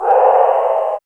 3005R HOWL.wav